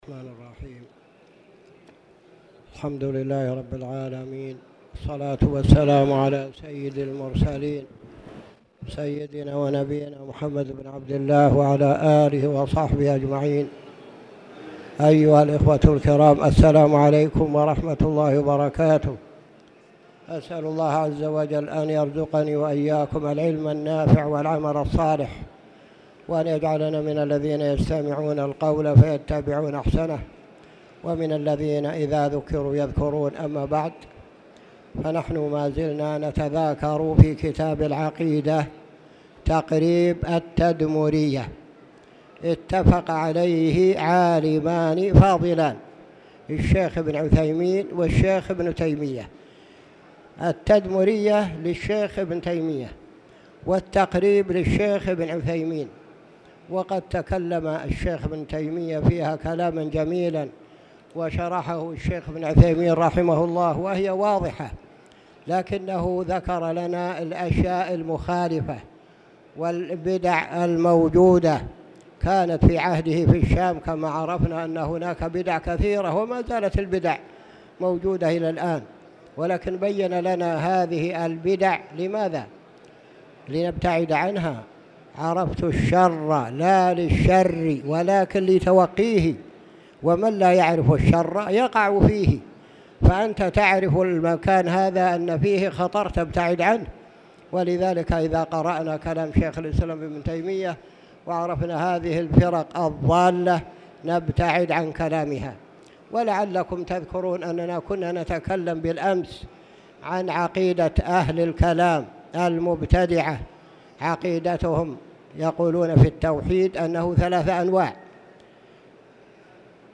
تاريخ النشر ٢٣ صفر ١٤٣٨ هـ المكان: المسجد الحرام الشيخ